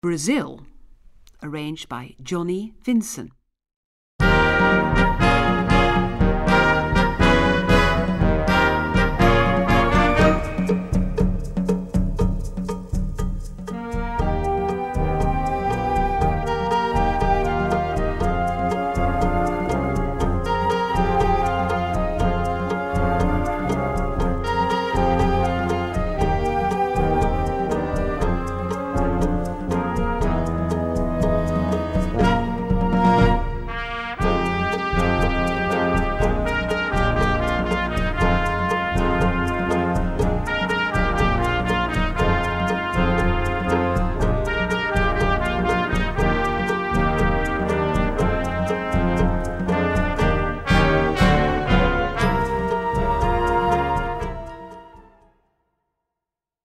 Gattung: Moderne Blasmusik
Besetzung: Blasorchester